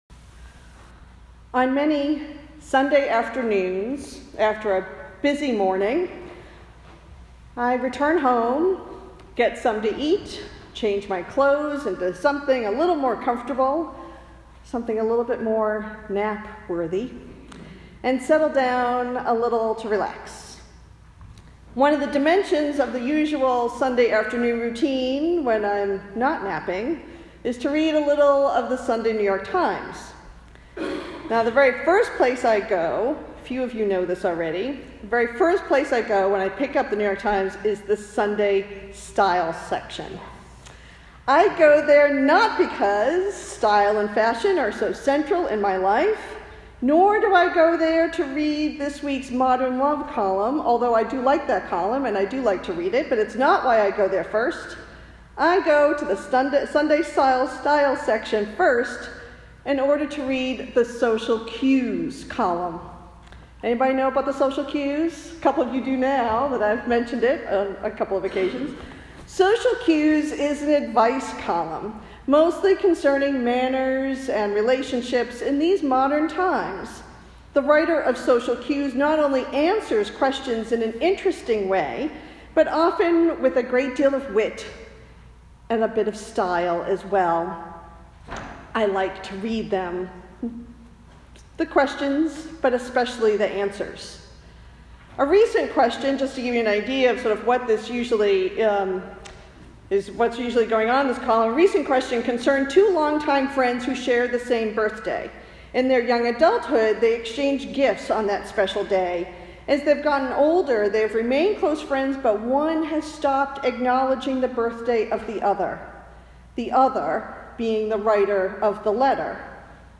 Related Posted in Sermons (not recent)